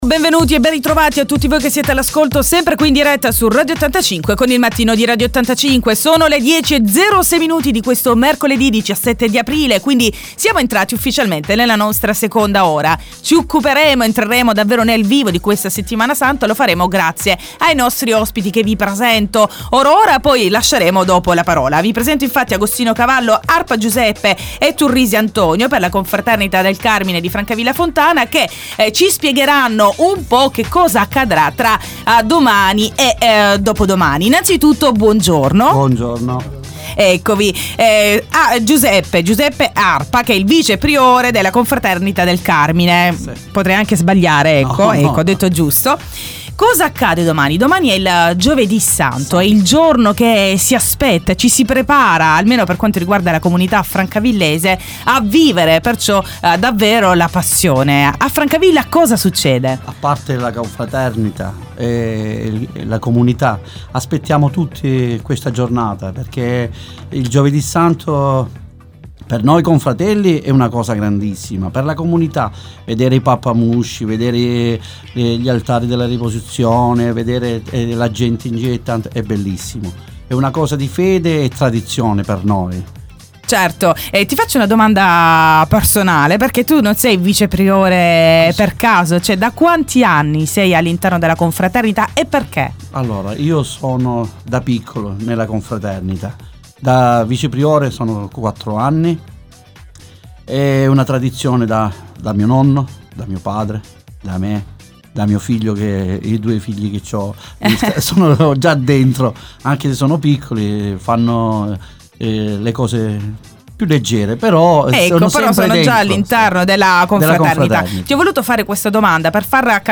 Intervista ai componenti della Confraternita del Carmine